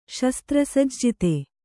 ♪ śastra sajjite